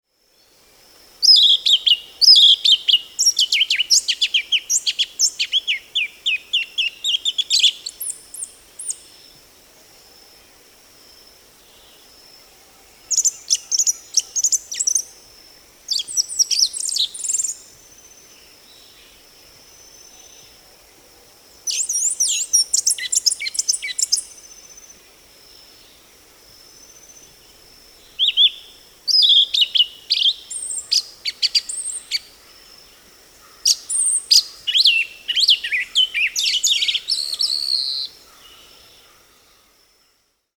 Sabiá-una
Na primavera e no verão, é presença marcante no alto das serras, onde entoa seu belo e complexo canto que incorpora a vocalização de outras aves.
Nome em Inglês: Yellow-legged Thrush
Aprecie o canto do